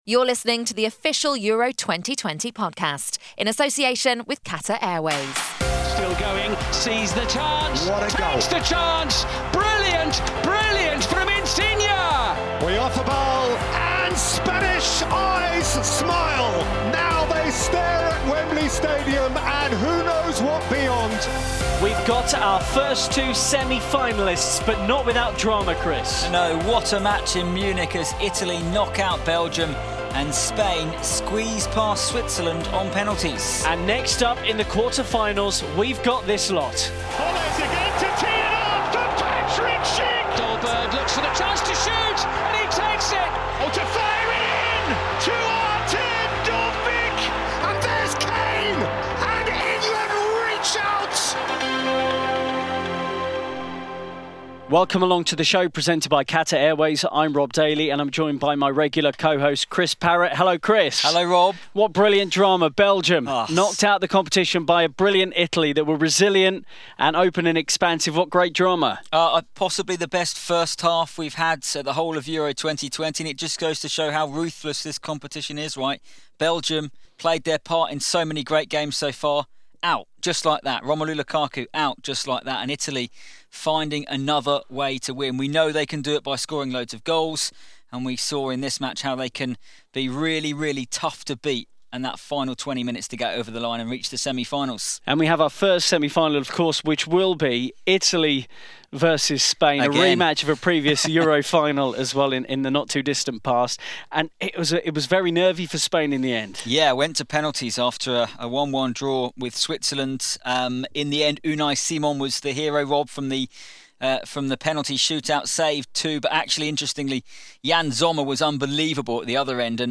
Gareth Southgate looks ahead to England's quarter-final against Ukraine, and we hear from Kasper Schmeichel as Denmark face the Czech Republic.